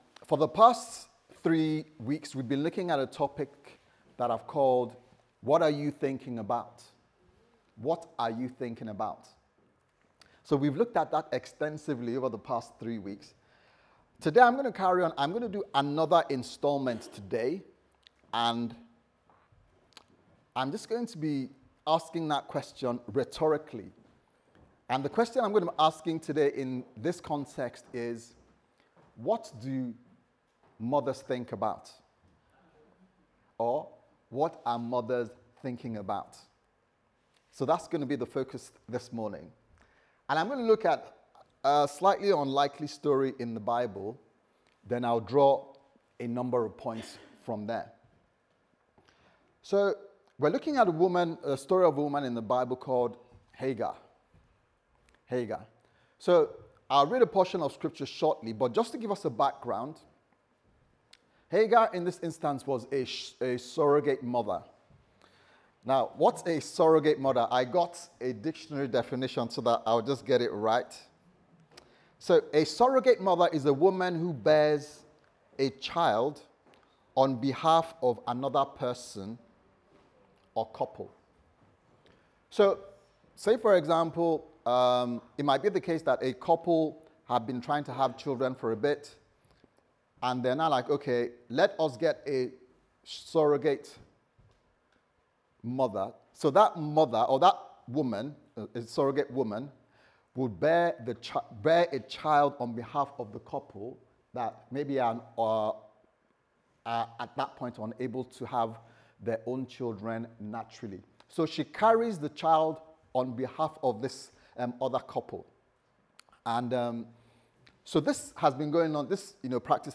What Are You Thinking About Service Type: Sunday Service Sermon « What Are You Thinking About